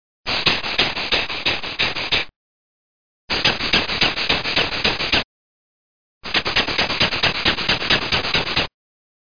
00246_Sound_panting.mp3